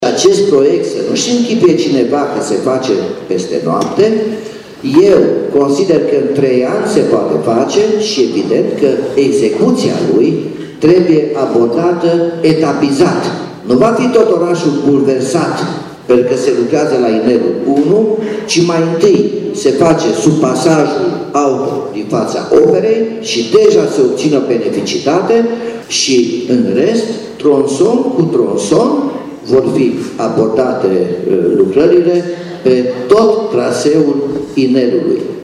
Consiliul Local a aprobat studiul de fezabilitate pentru proiectul care prevede o investiție estimată la 50 de milioane de euro și modificări majore în centrul istoric al Timișoarei. Potrivit primarului Nicolae Robu, sunt șanse ca în viitor să se circule prin pasajul construit sub Piața Operei: